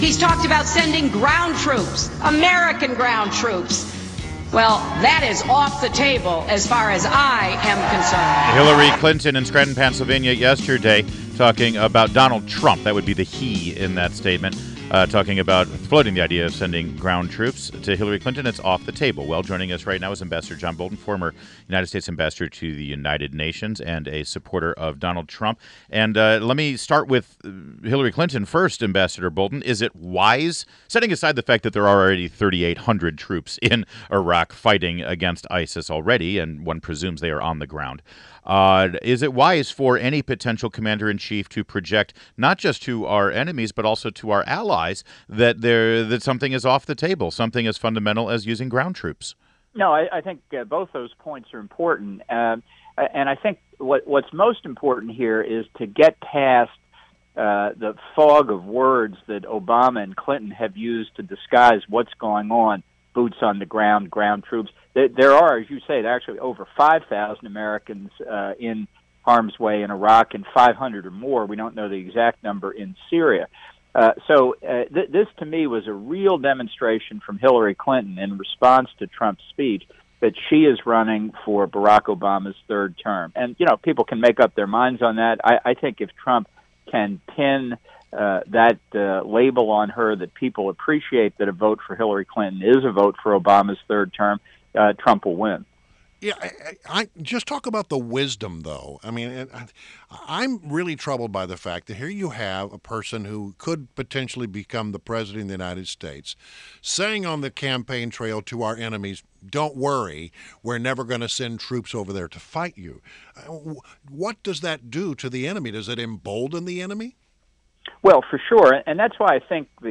WMAL Interview - Amb. John Bolton - 08.17.16